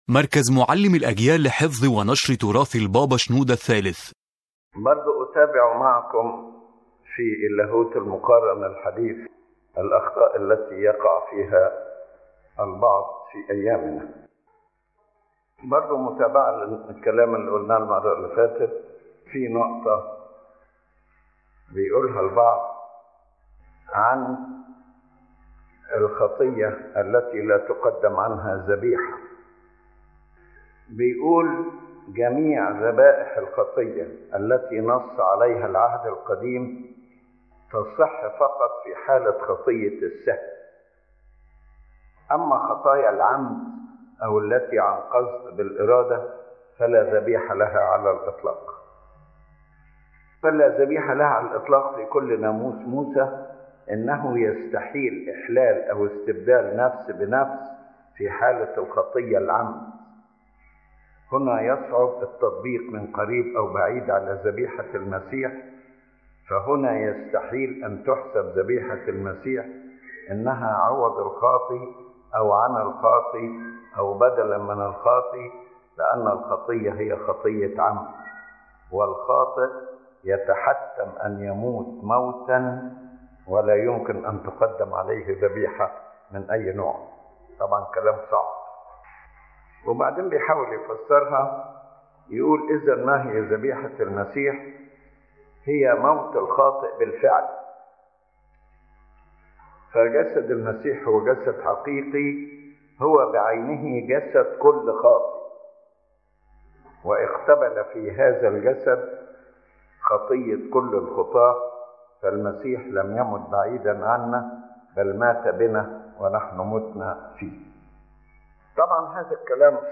⬇ تحميل المحاضرة أولًا: ذبيحة المسيح تشمل كل الخطايا يتناول قداسة البابا شنوده الثالث فكرة تقول إن خطايا العمد لا تُقدَّم عنها ذبيحة، وأن ذبائح العهد القديم كانت تقتصر على خطايا السهو فقط.